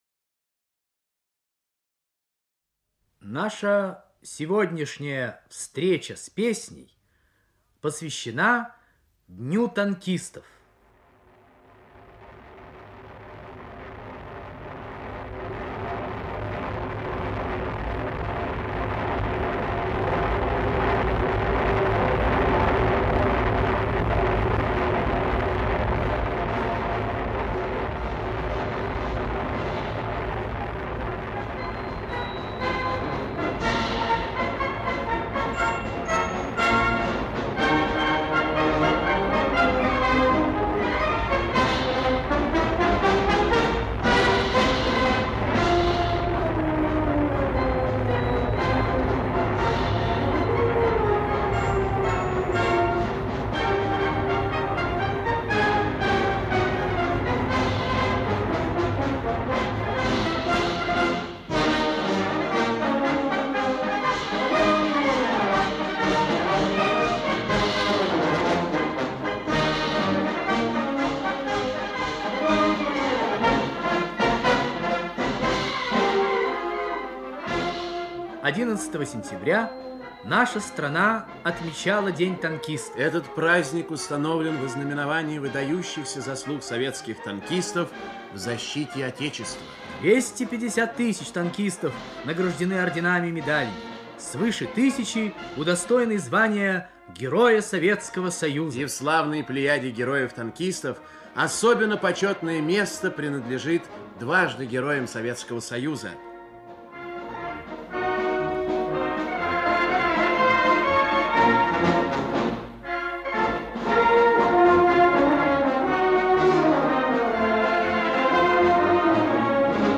Музыкальная передача, посвященная Дню танкистов.
Звучат песни советских композиторов.
Использованы фондовые записи.
Духовой оркестр.